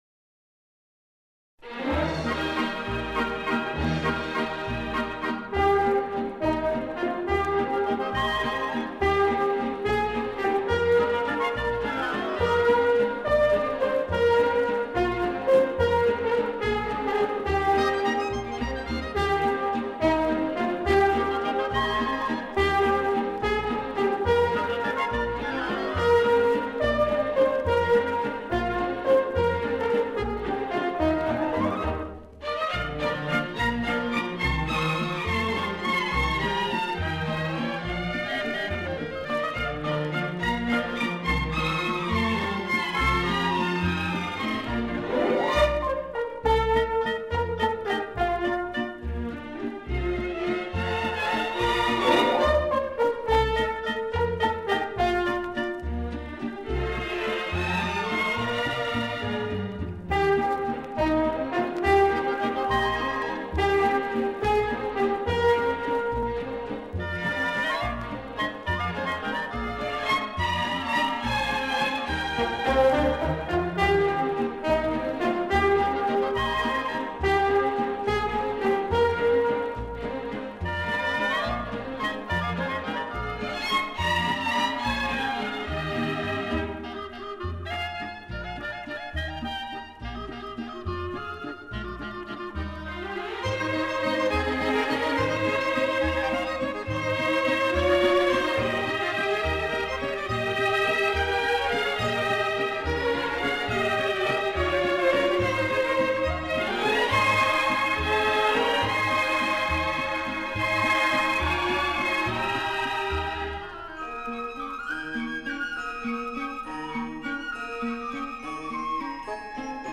Genre:World Music